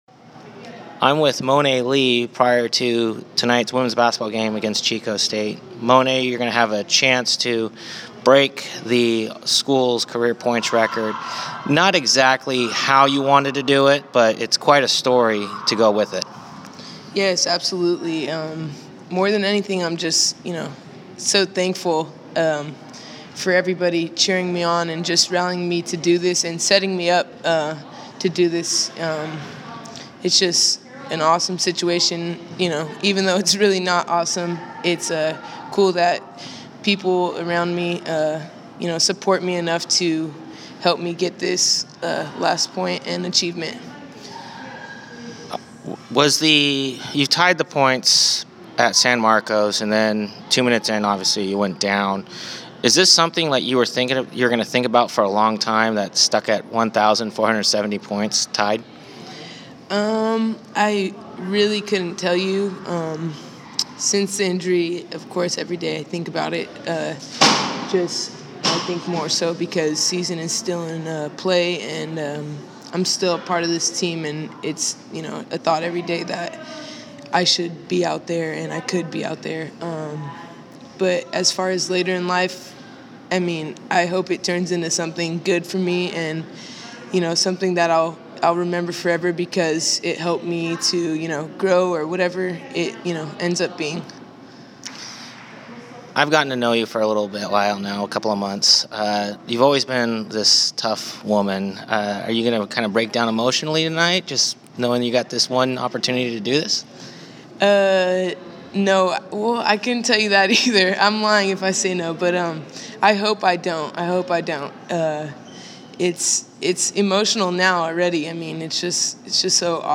fighting back her emotion before tonight's game